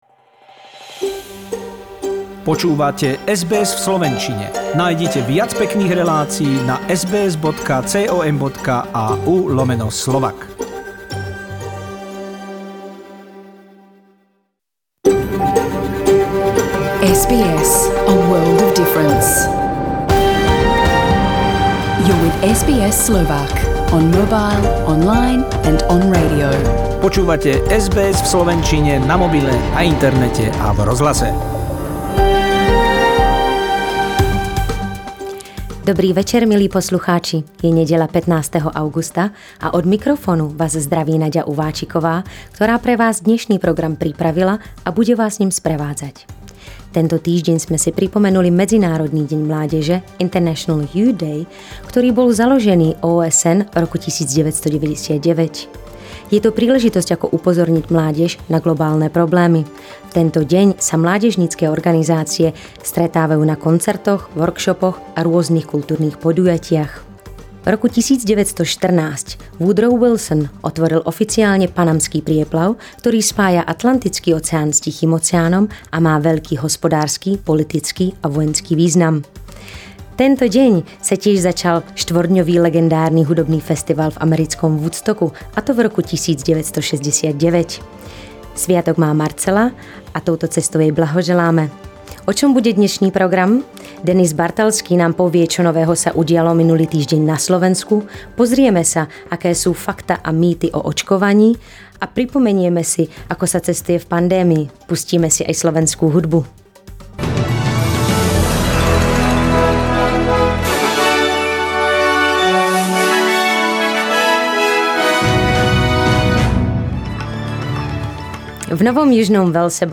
Súhrn správ z Austrálie a zo sveta od SBS News 15.8.2021